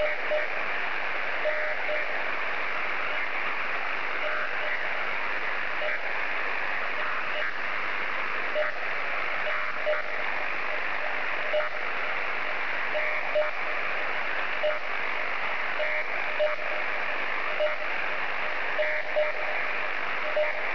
UNID DATA SOUND ON 3610